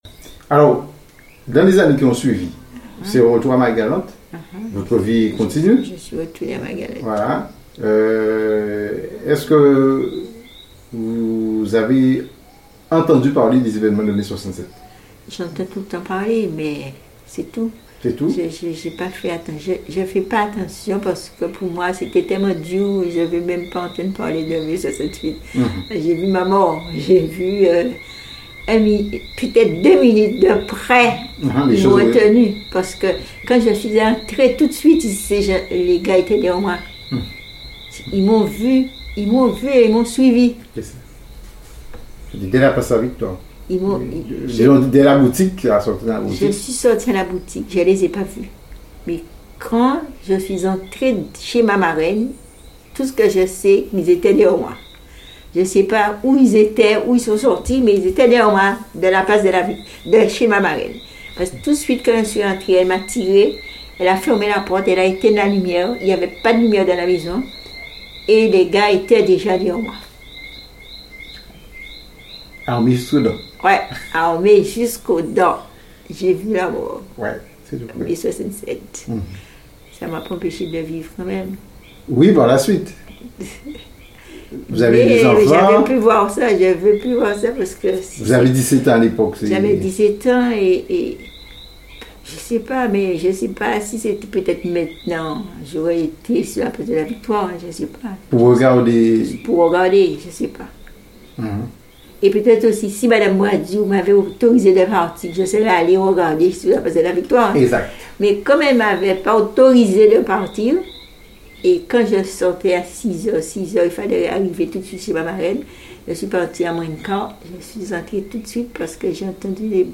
Témoignage sur les évènements de mai 1967 à Pointe-à-Pitre.
Intégralité de l'interview.